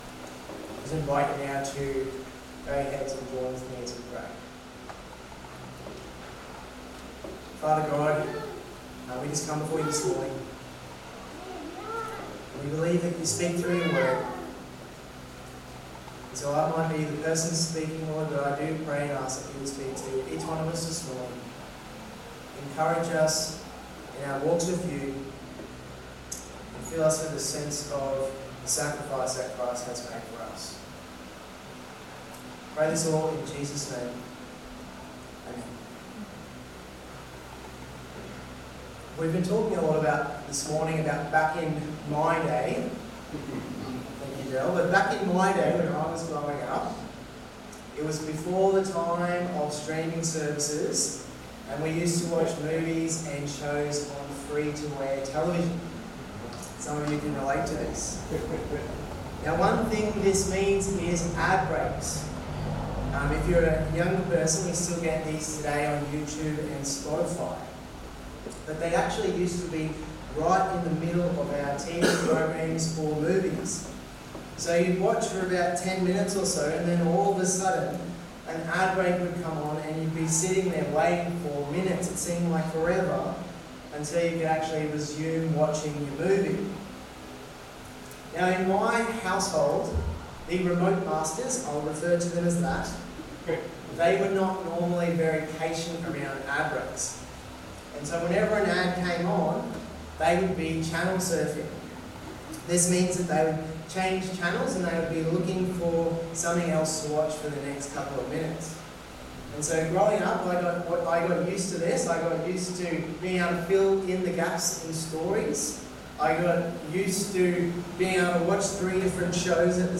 Plots, Pain, and Passover (Luke 22:1-23 Sermon) 19/03/2023